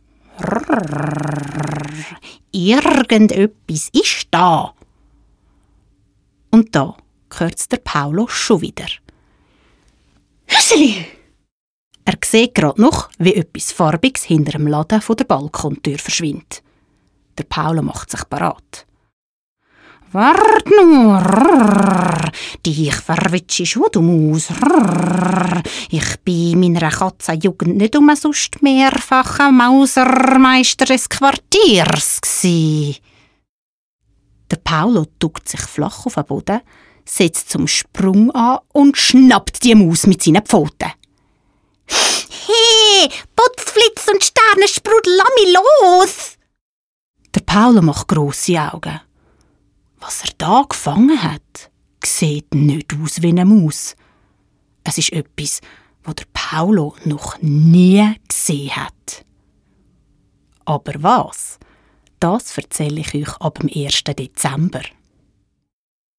✩ Potz Flitz und Stärnesprudel 1 ✩ Mundart Geschichte ✩ Advent
Hinweis: Dies ist die Adventskalender-Geschichte Potz Flitz und Stärnesprudel 1 – De verflitzt Advent als leicht gekürzte, durchlaufende Geschichte (ohne Ansagen: „Hüt isch de 1. Dezember“, etc.)